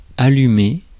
Ääntäminen
IPA : /ˈkɪndl/